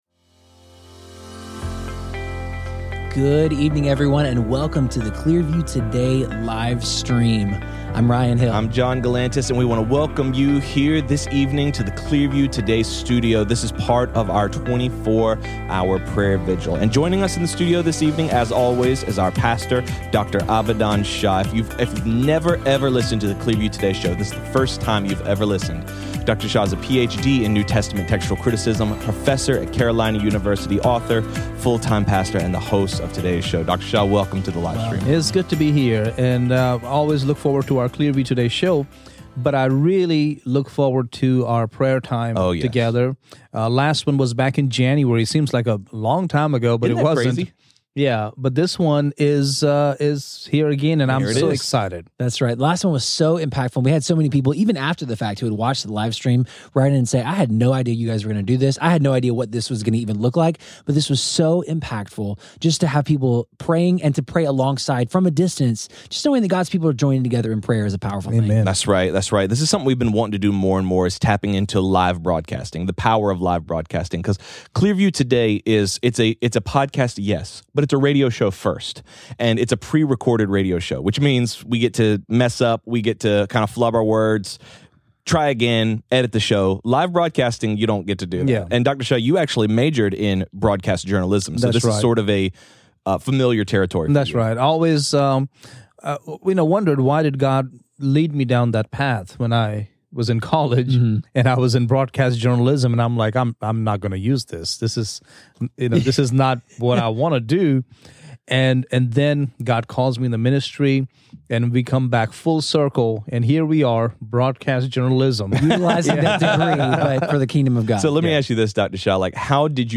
BONUS: 2 Hour Prayer Vigil Livestream